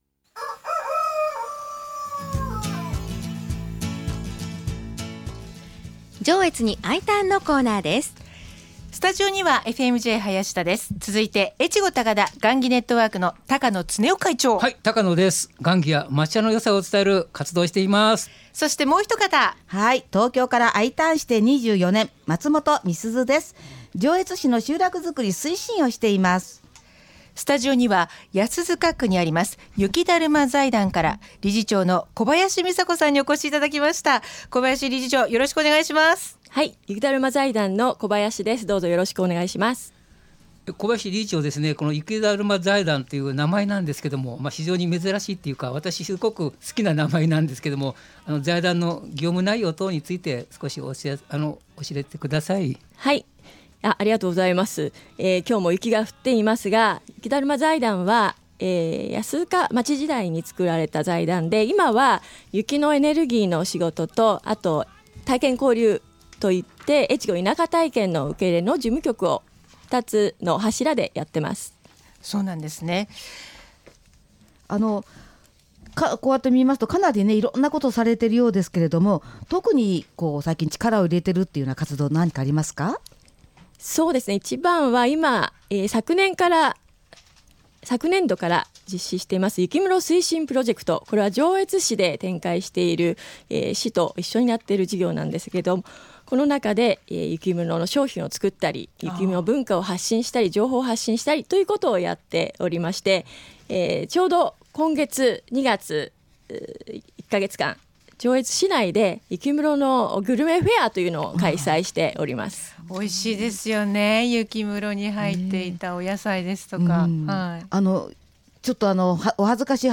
FM-Jのスタジオから移住をお誘いするコーナー。